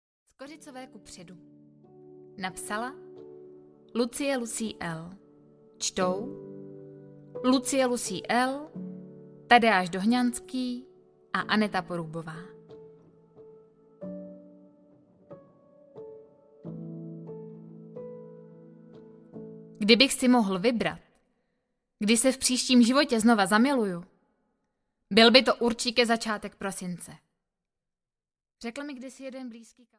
Skořicové kupředu audiokniha
Ukázka z knihy